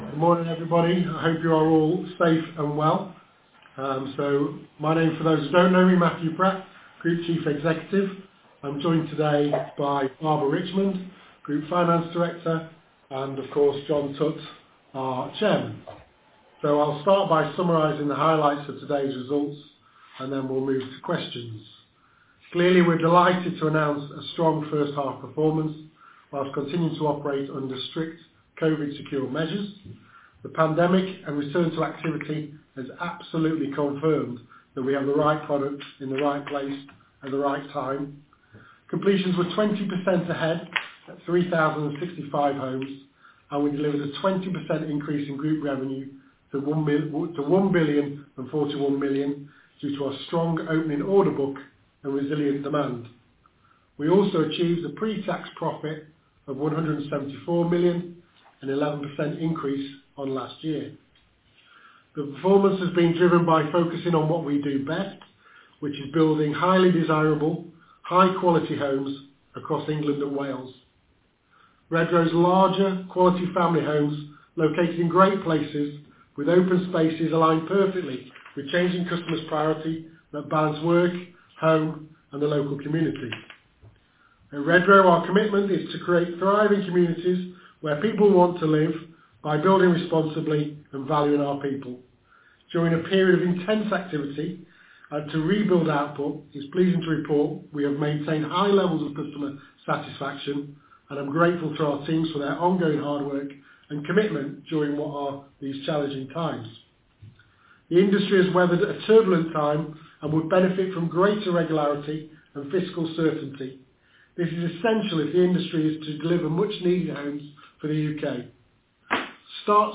Analyst Q&A